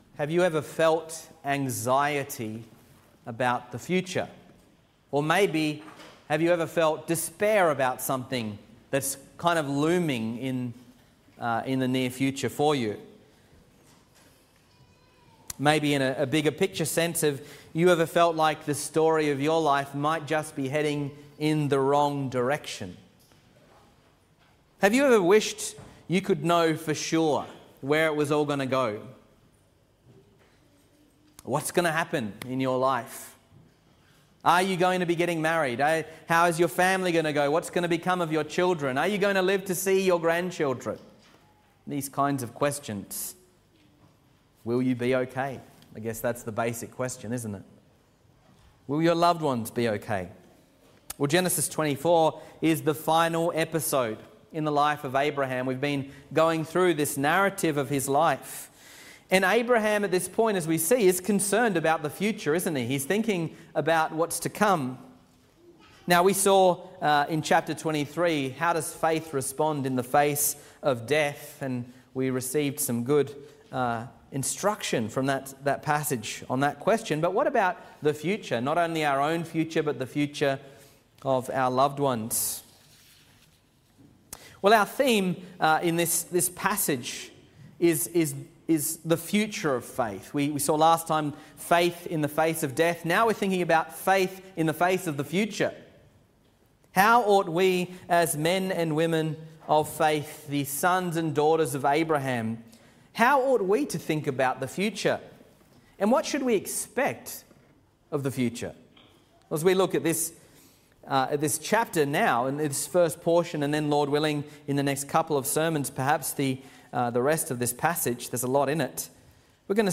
Sermons | Reformed Church Of Box Hill
Morning Service